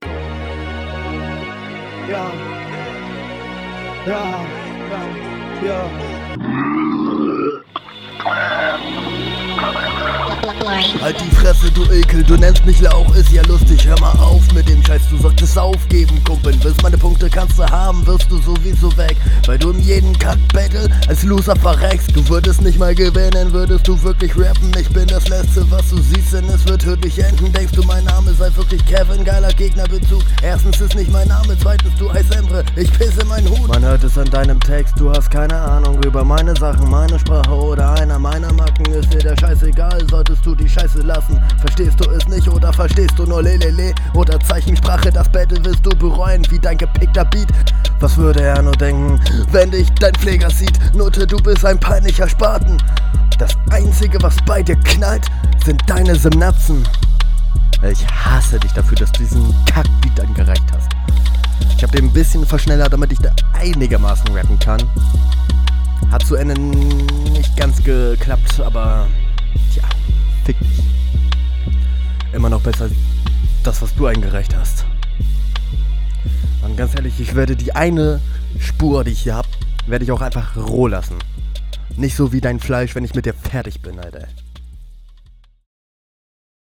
Beat für Rückrunde ändern = keinen Flowpunkt wert
Leider stark offbeat.
Deine Stimme klingst als würdest du Flüster-schreien?